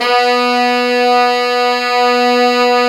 Index of /90_sSampleCDs/Roland L-CDX-03 Disk 2/BRS_Pop Section/BRS_Pop Section1